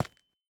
Minecraft Version Minecraft Version 21w07a Latest Release | Latest Snapshot 21w07a / assets / minecraft / sounds / block / calcite / step5.ogg Compare With Compare With Latest Release | Latest Snapshot
step5.ogg